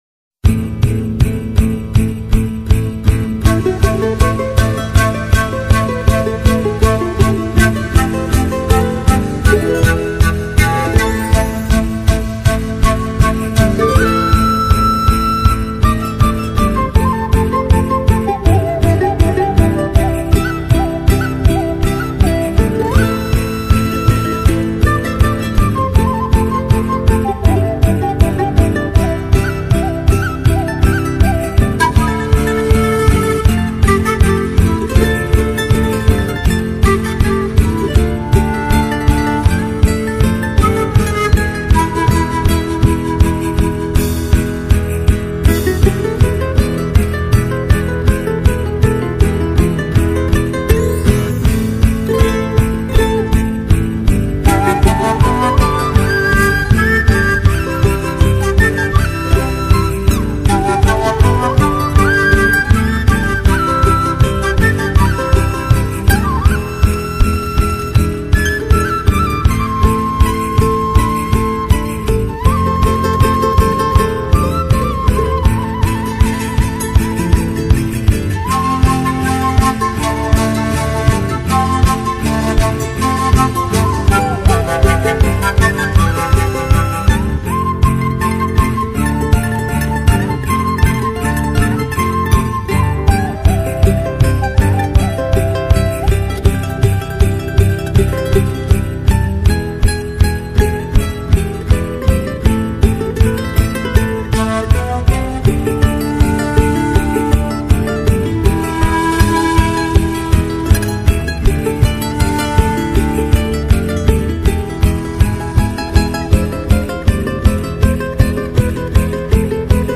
CHARANGO, QUENA Y ZAMPOÑA